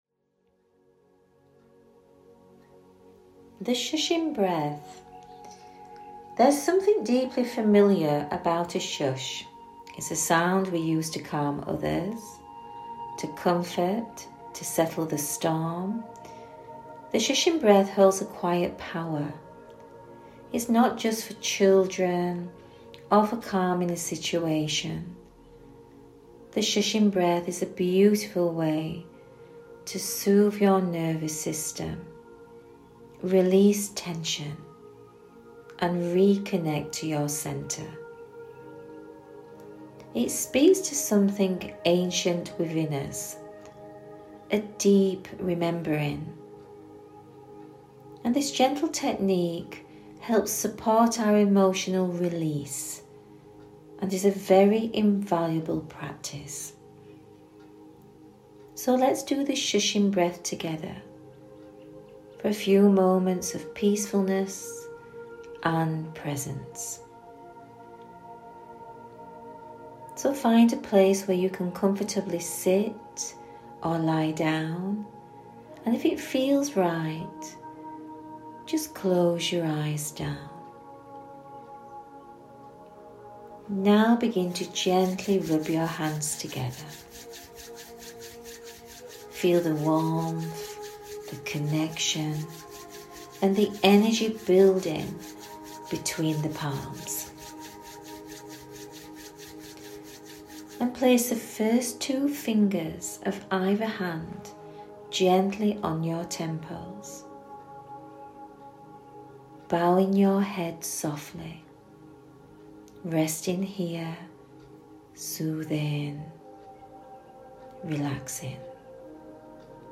With each slow breath in and long, slow, audible “shhhh” out, you begin to calm the nervous system, soften stored tension, and release emotional weight that’s may have been building up for sometime, even years.
• Notice your breath and begin taking slow, deep breaths in through your nose and exhaling slowly through your mouth, making a long, soft “shhhhh” sound.
The Shushing Breath.mp3